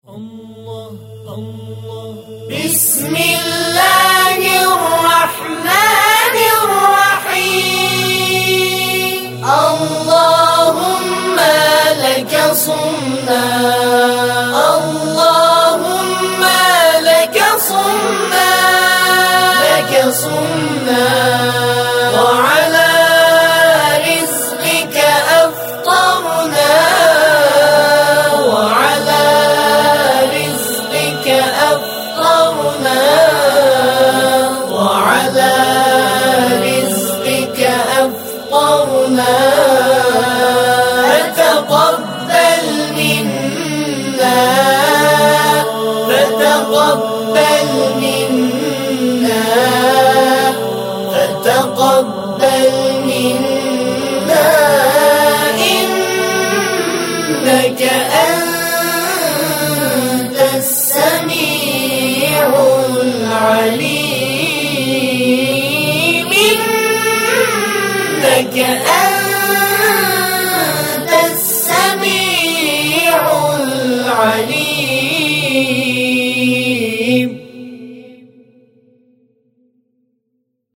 تواشیح
اجرا شده توسط نوجوانان این گروه